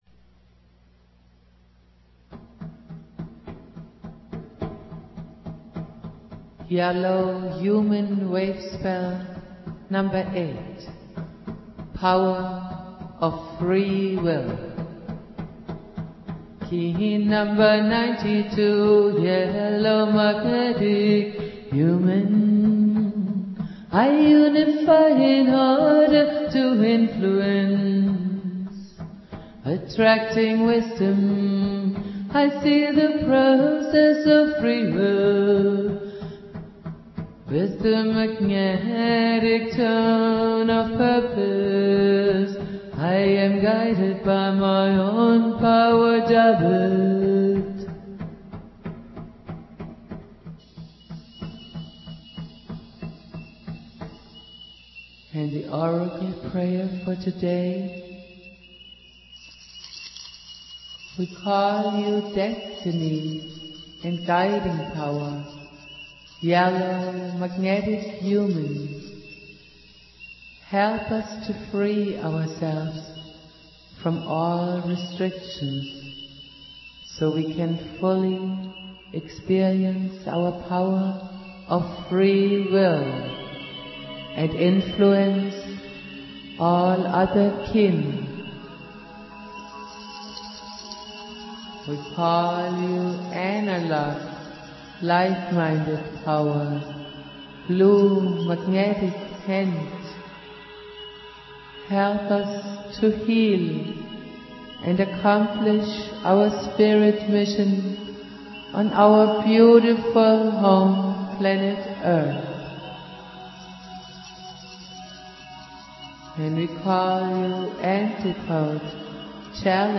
flute
produced at High Flowing Recording Studio